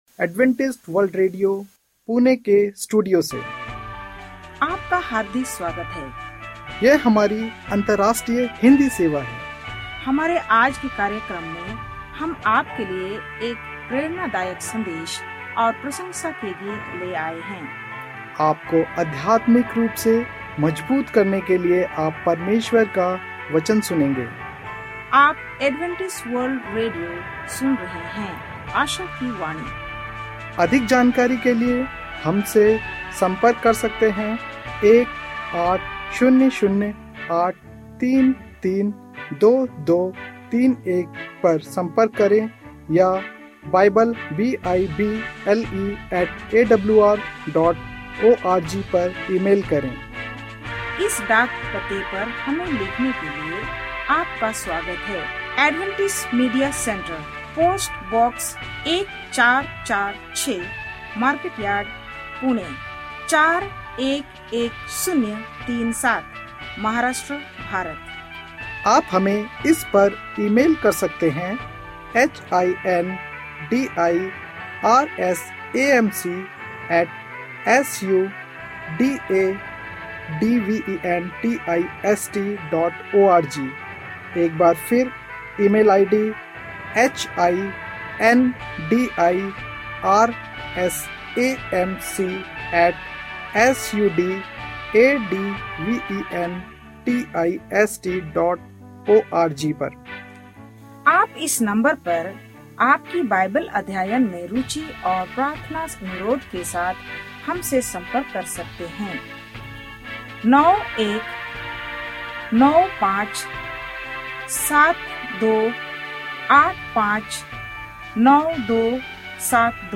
Hindi radio program from Adventist World Radio